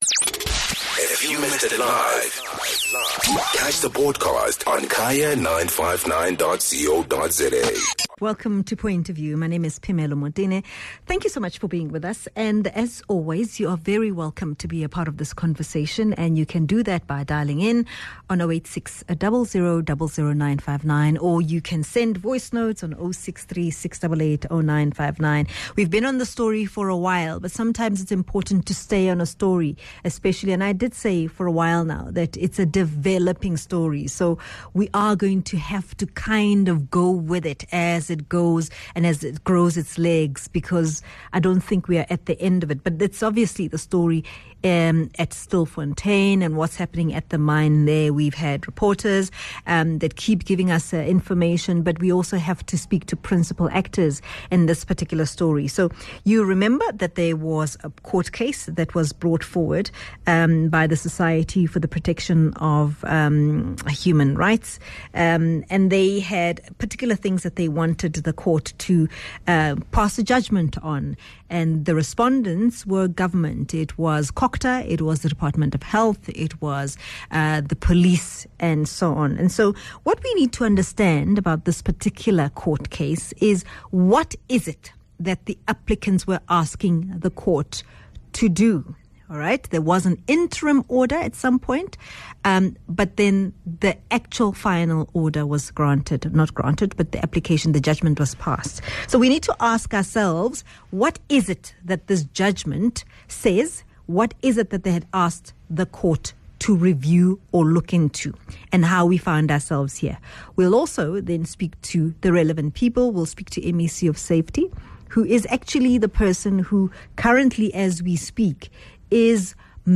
speaks to legal expert